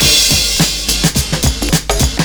106CYMB01.wav